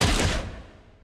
cargo-bay-hatch-opening-stop.ogg